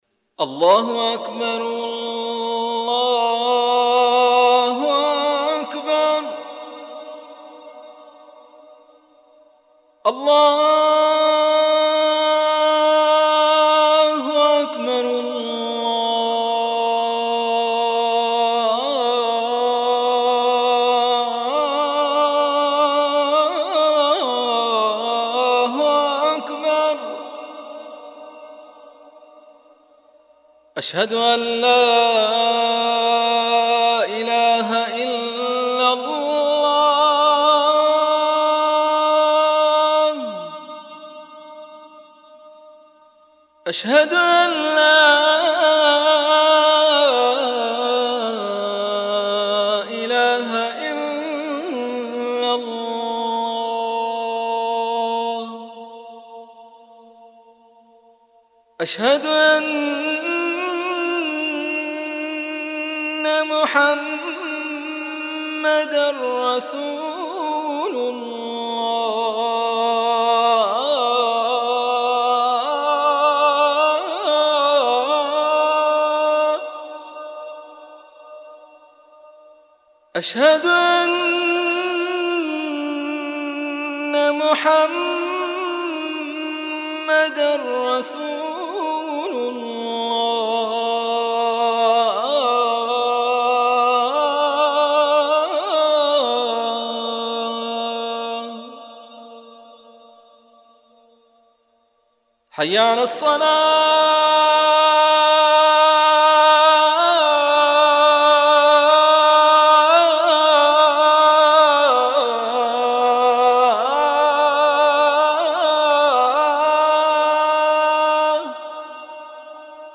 آذان